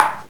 drum-hitwhistle.ogg